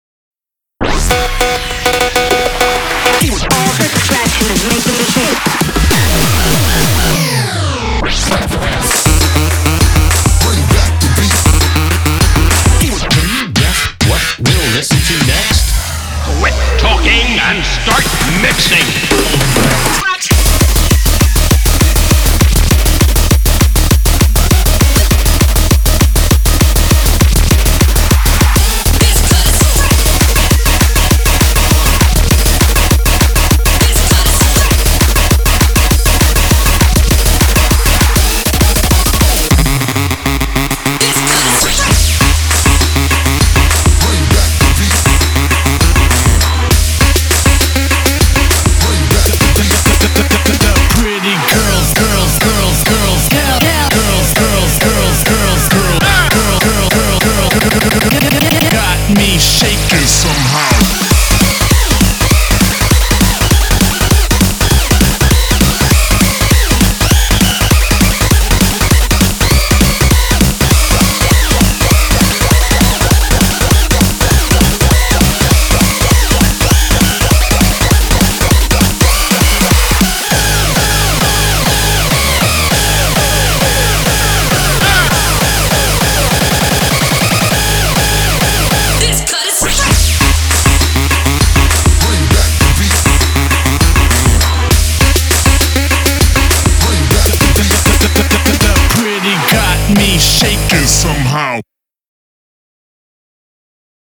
BPM100-200